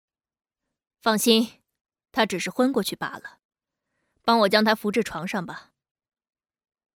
女声
御姐-天命奇御-刘二娘